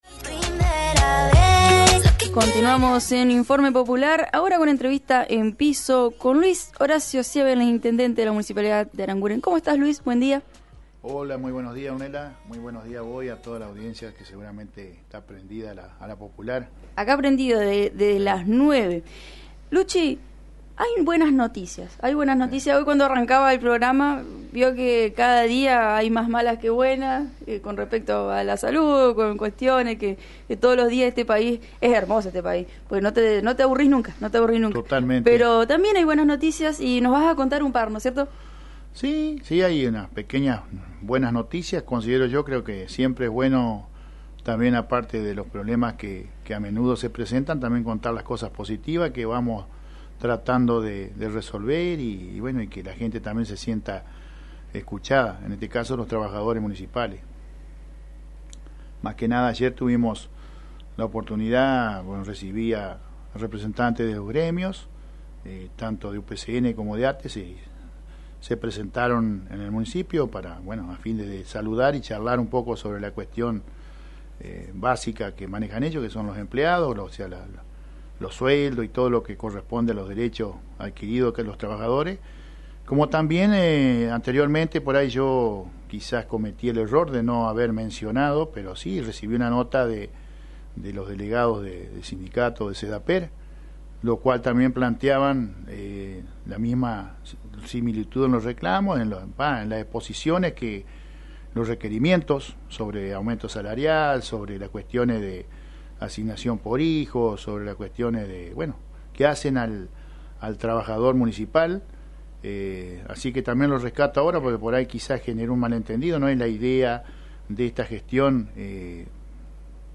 Declaraciones del intendente a la radio municipal: